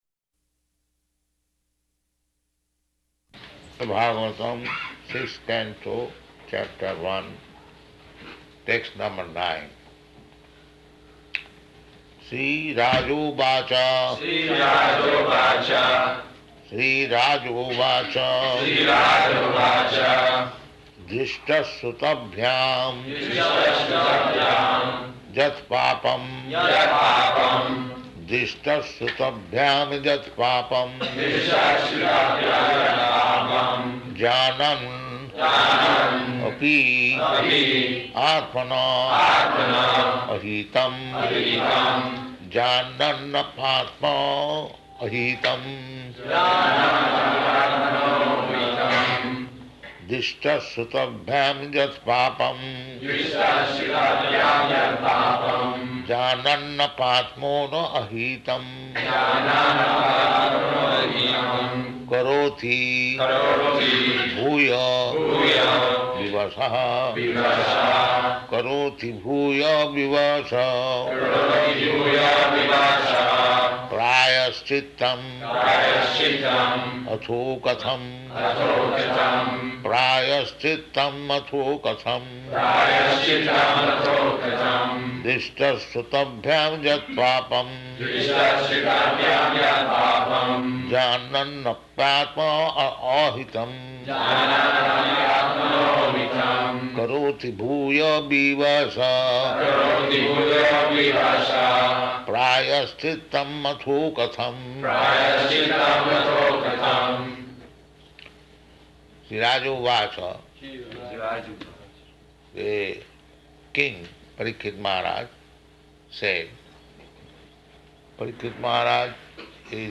Śrīmad-Bhāgavatam 6.1.9 --:-- --:-- Type: Srimad-Bhagavatam Dated: May 10th 1976 Location: Honolulu Audio file: 760510SB.HON.mp3 Prabhupāda: Śrīmad-Bhāgavatam, Sixth Canto, Chapter One, text number nine.